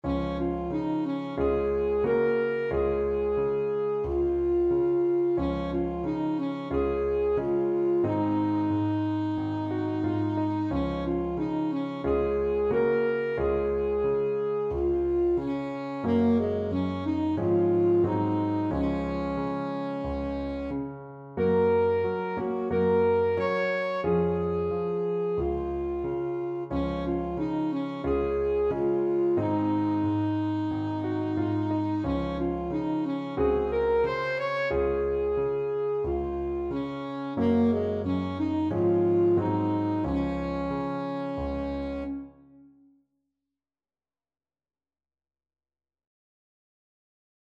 Alto Saxophone
Db major (Sounding Pitch) Bb major (Alto Saxophone in Eb) (View more Db major Music for Saxophone )
Andante =c.90
4/4 (View more 4/4 Music)
Traditional (View more Traditional Saxophone Music)
Lullabies for Alto Saxophone